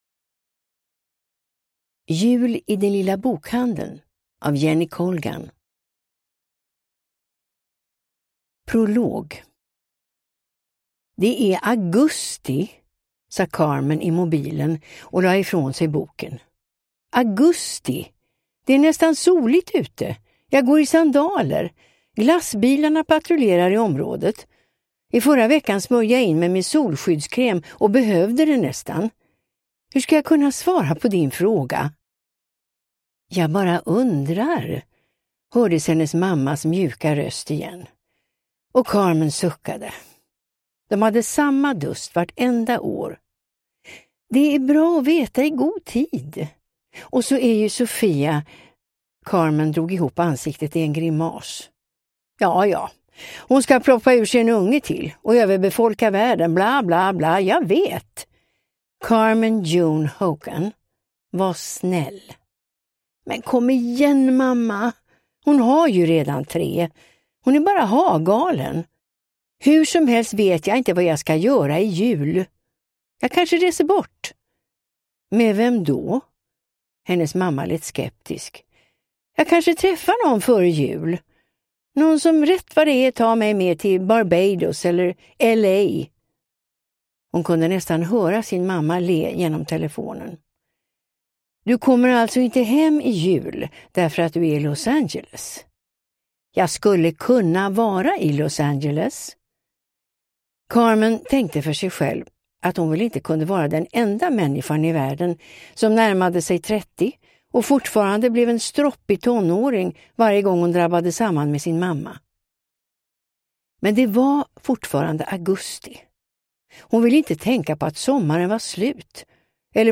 Jul i den lilla bokhandeln – Ljudbok – Laddas ner